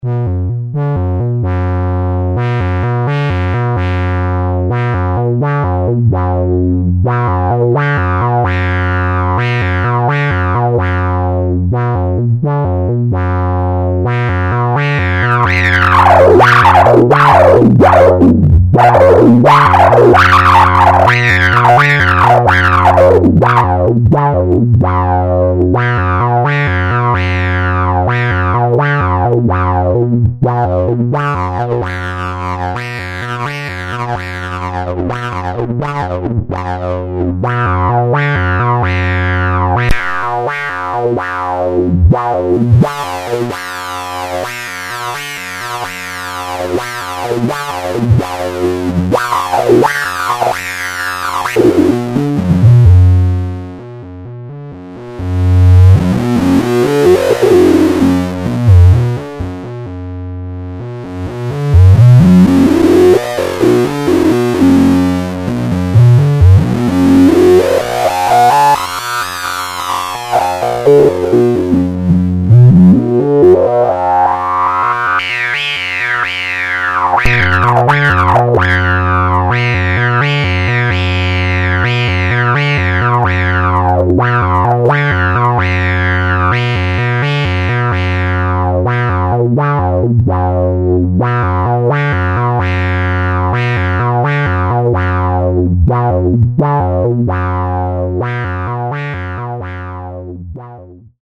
yesterday i found a little time to record a short sound sample of the different settings. lp, res normal/krank, bp, with/without different distortion stations mixed in.
nothing fancy, from VCF direct into soundcard, just fiddling Wink